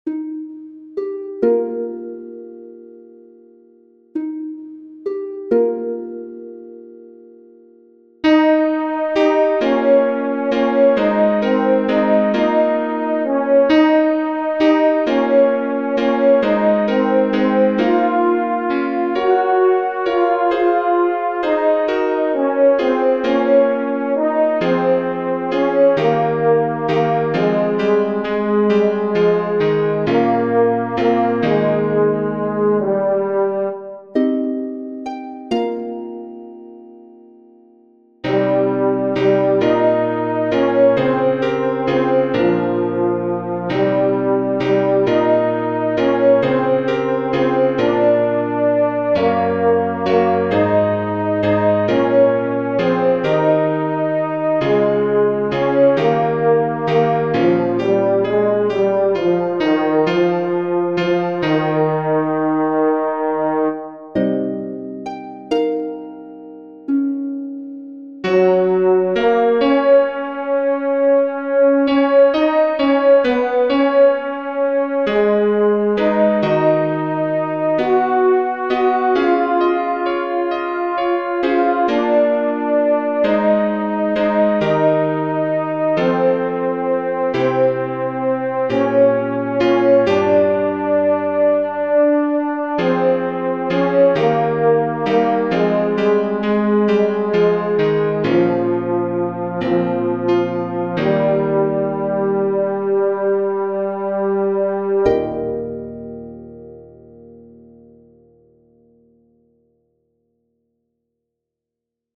Tenor
the_call-tenor.mp3